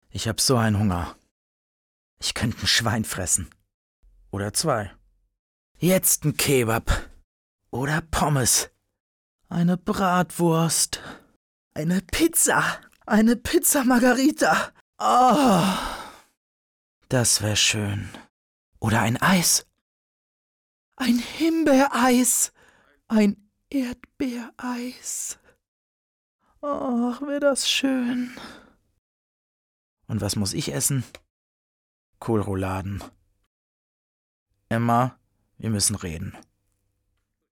Meine Stimme klingt unverfälscht, jung und sympathisch und ist vielseitig einsetzbar für jugendliche bis männlich-zärtliche Charaktere.
Improvisation. „Ich habe Hunger“
Castingaufnahme
Diverse Facetten